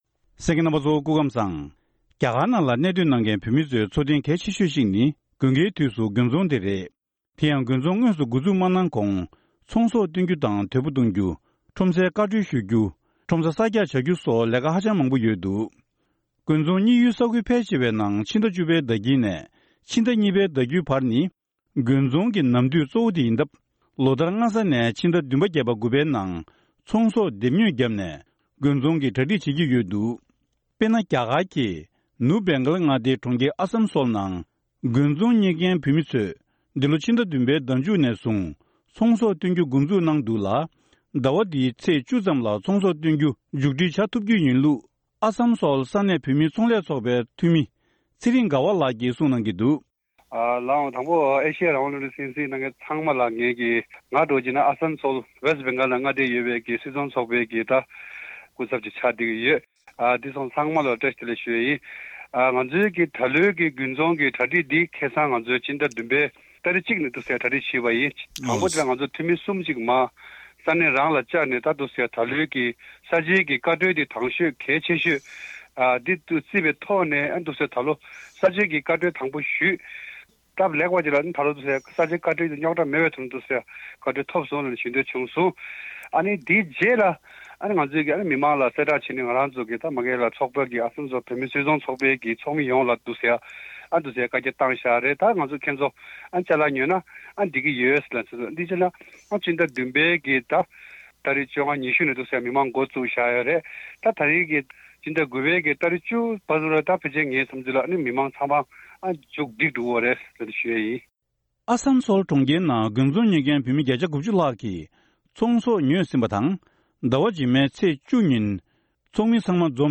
བཀའ་འདྲི་ཞུས་ ནས་ཕྱོགས་སྒྲིགས་ཞུས་པ་ཞིག་གསན་རོགས་གནང་།